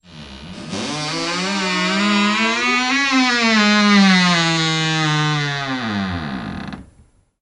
Звуки шкафа
Скрипт дверцы шкафа